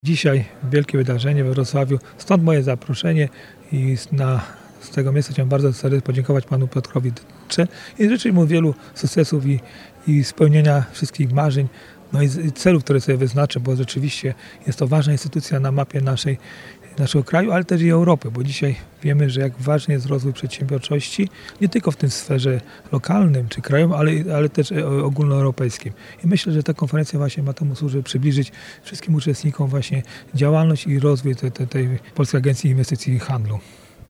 – To bardzo ważne wydarzenie, a konferencja na pewno przybliżyła działalność Polskiej Agencji Inwestycji i Handlu – dodaje Marek Długozima, burmistrz Trzebnicy.